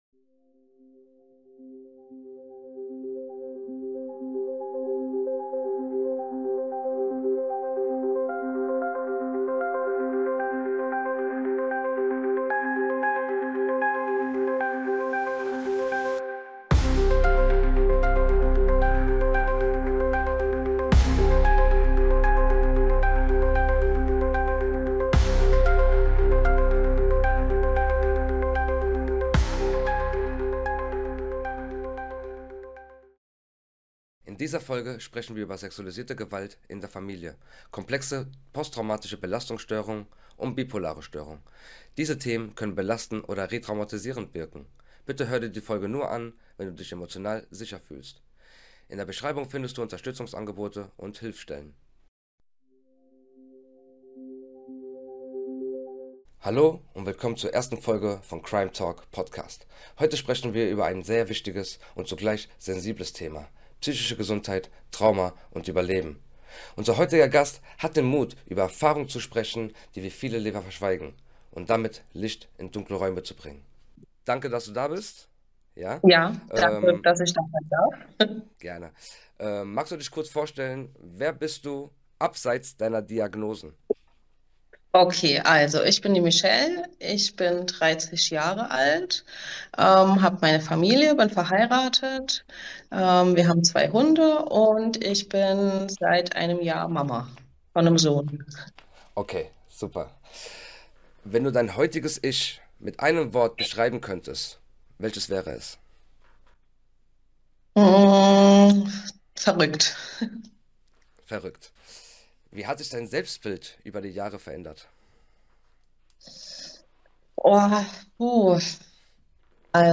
In dieser ersten Folge unseres zweiteiligen Gesprächs spricht eine Betroffene mit außergewöhnlichem Mut über ihre Kindheit, geprägt von sexuellem Missbrauch durch ein enges Familienmitglied. Wir sprechen über das Schweigen, das viele Jahre herrschte – und was es bedeutet, diesem Schweigen endlich eine Stimme entgegenzusetzen.